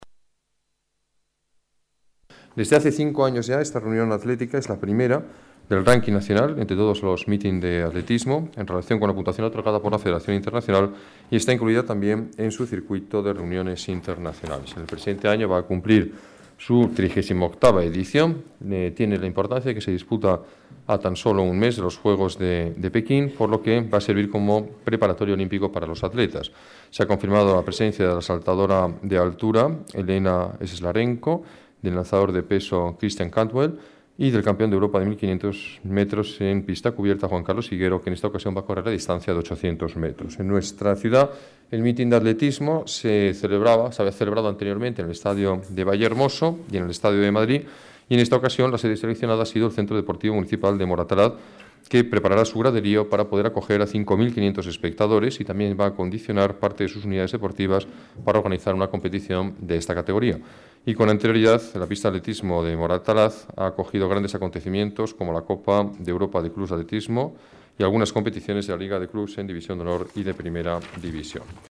Nueva ventana:Declaraciones alcalde, Alberto Ruiz-Gallardón: Meeting Atletismo